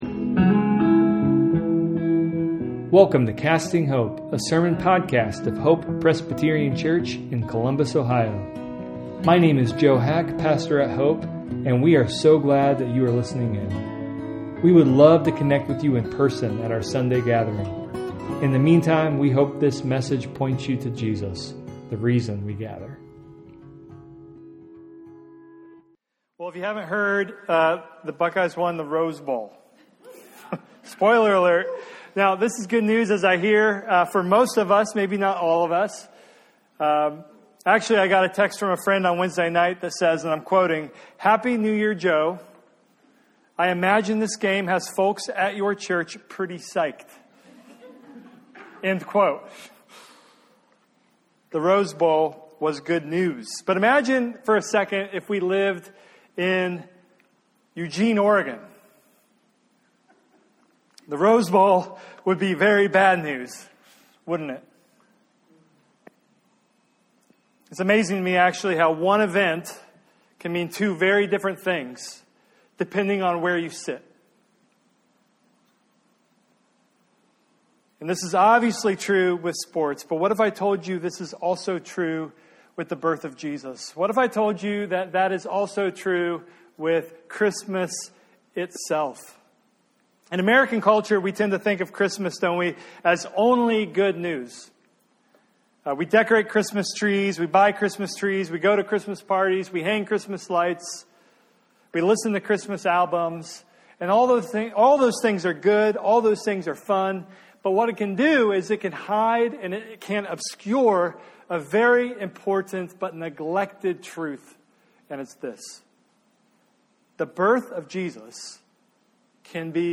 A sermon podcast of Hope Presbyterian Church in Columbus, Ohio.